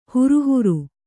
♪ huru huru